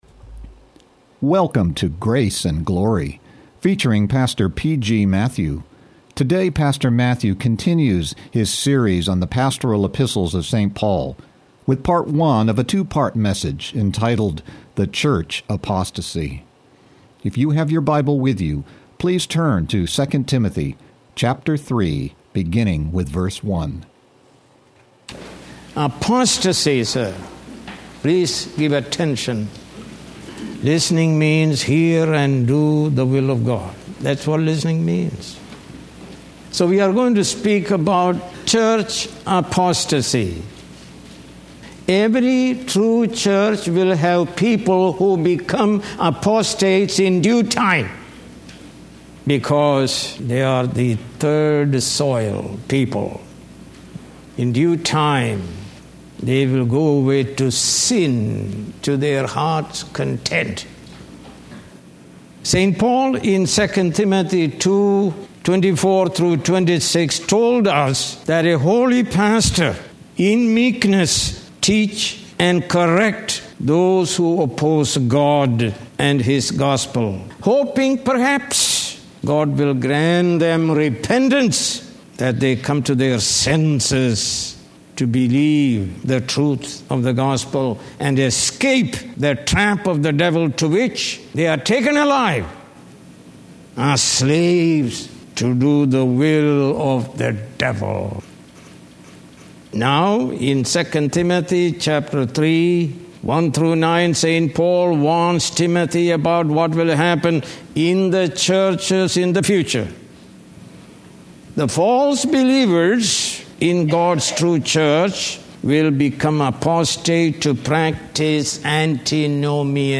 More Sermons